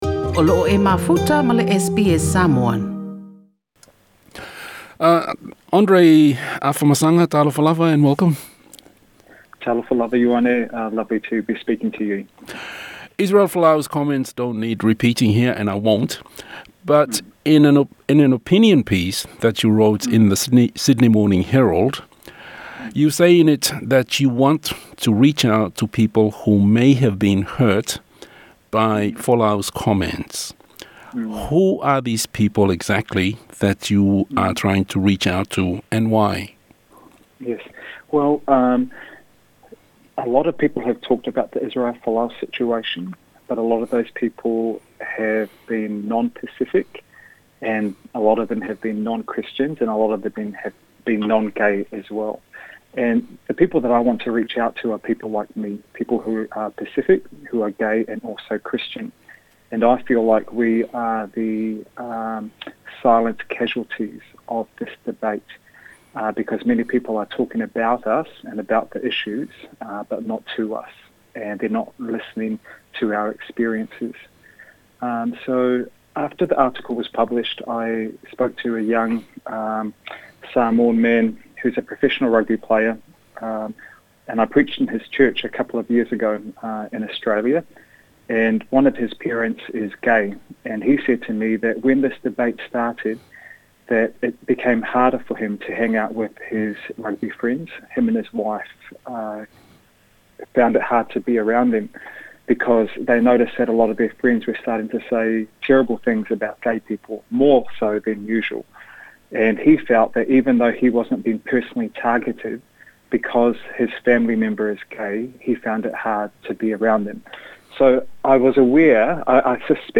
Talanoaga